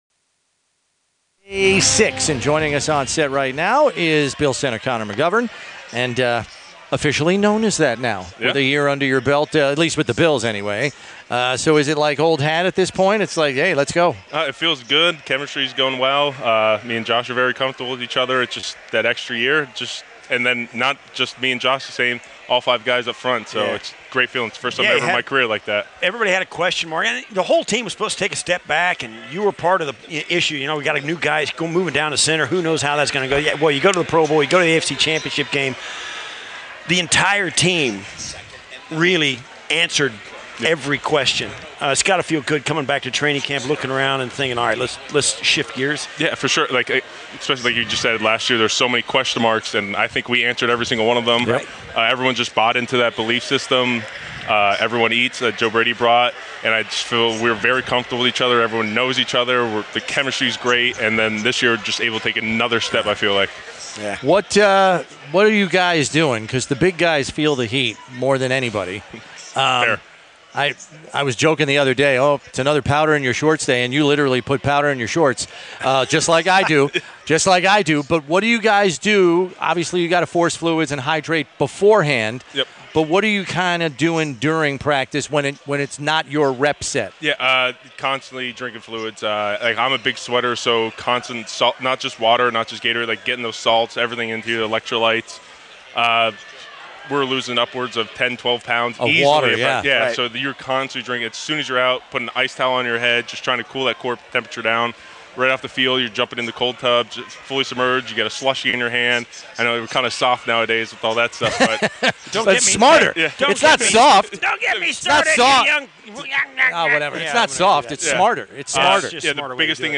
Bills Players/Coaches Interviews on WGR: July 28-August 1